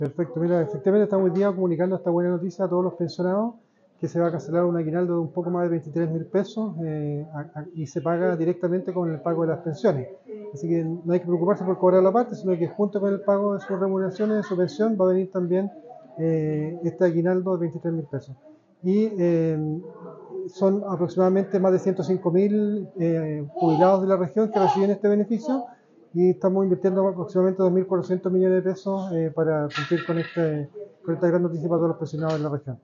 En este sentido el director de Instituto de Previsión social de la región de Coquimbo, Juan Paulo Garrido, explicó que